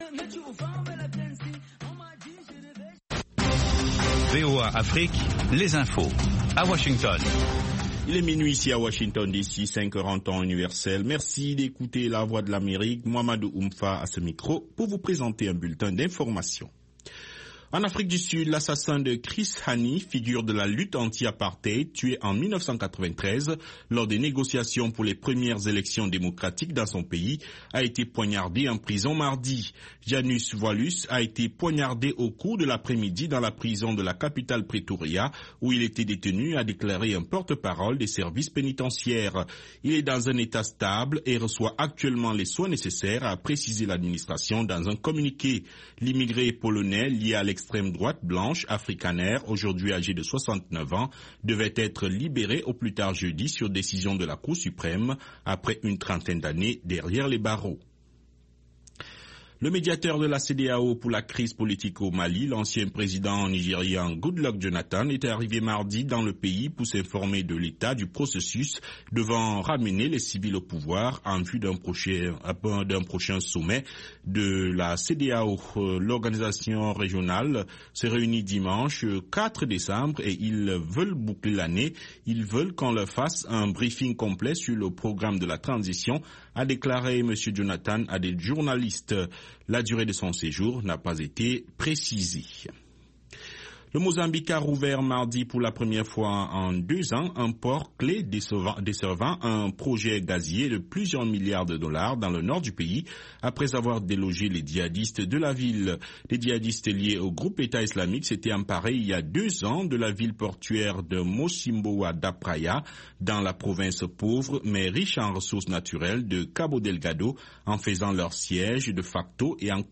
5min Newscast